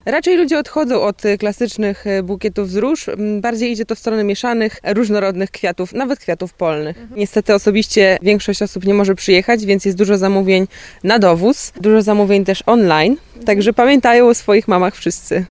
Jeśli wahacie się, jaki bukiet wybrać – kwiaciarka z Giżycka służy podpowiedzią.